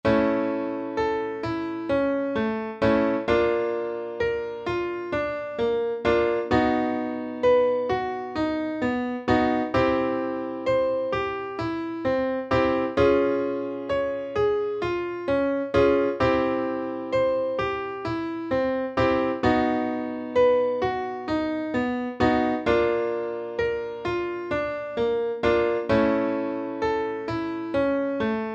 Śpiewamy
ćwiczenie „Mama moja” od dźwięku A2 do E3 powrót do A2